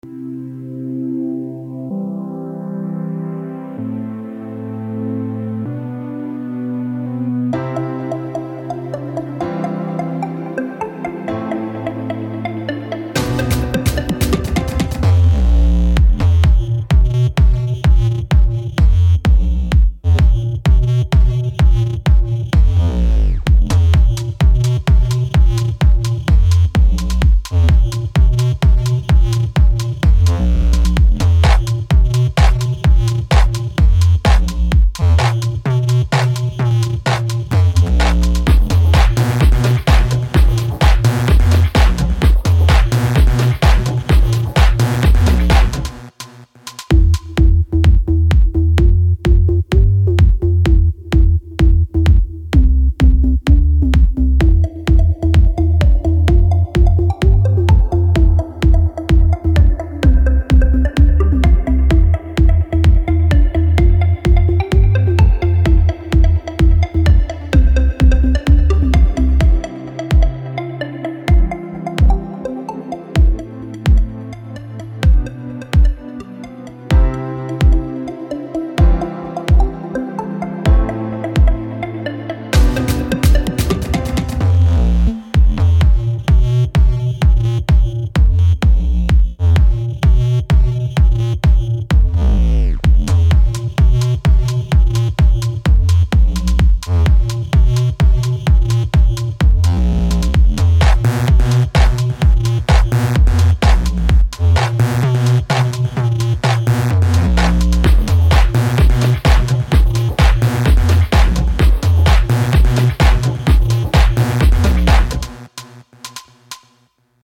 EDM download